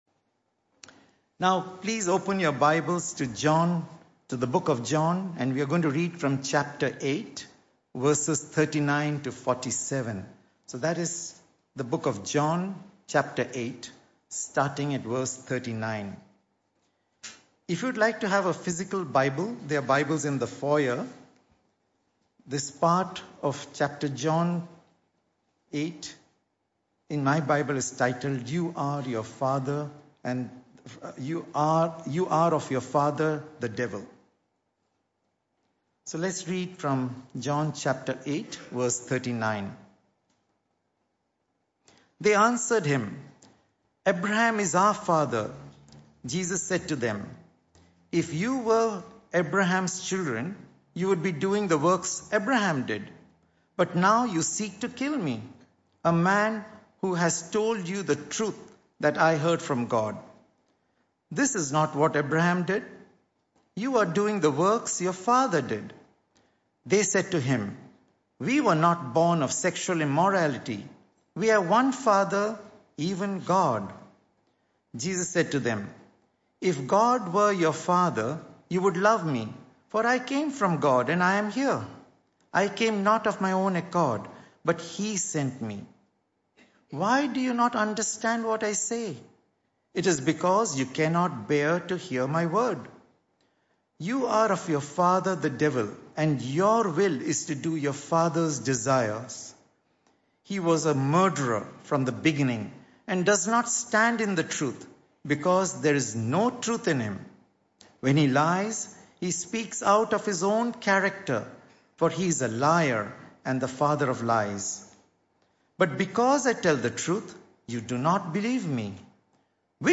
This talk was the first in the AM Services series entitled The Truth About Lies.